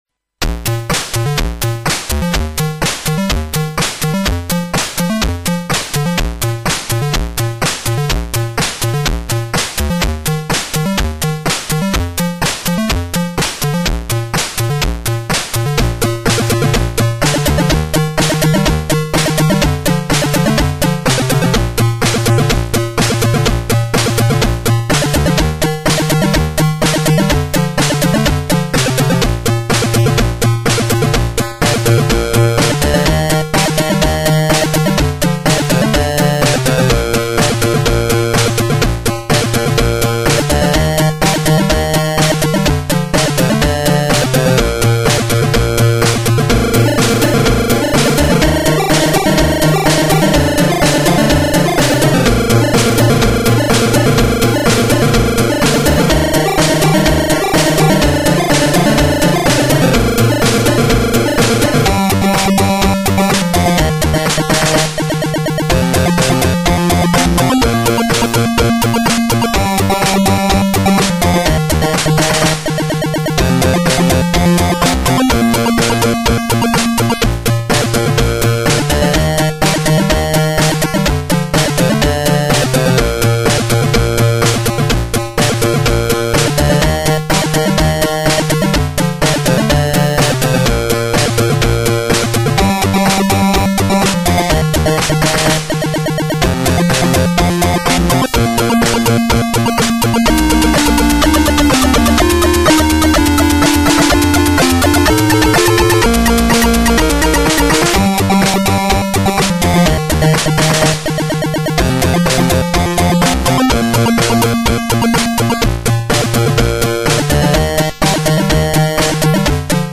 All sounds recorded using line-in of PC.
2 channel version
Sound Example 2 (Emulator version 11.1 2 channel 27MHz)